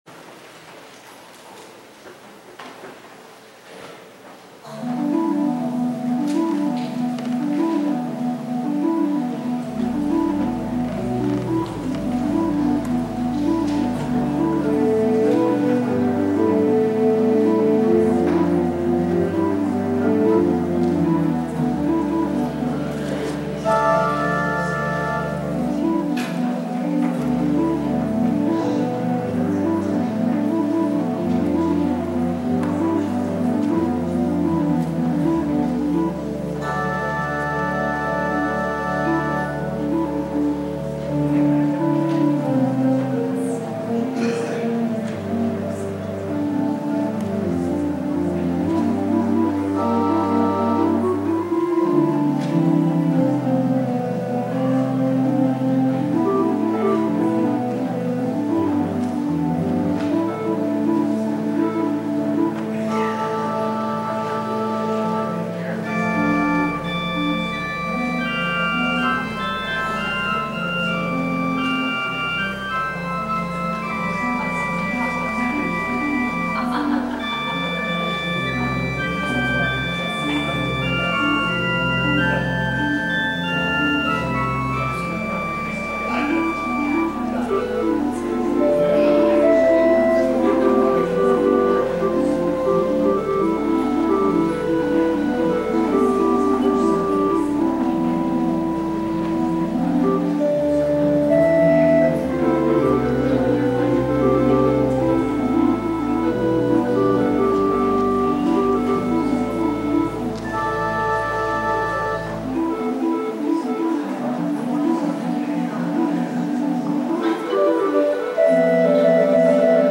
Took me this long to edit the musical clips I wanted from our Easter services to share here.
organist
communion-music.mp3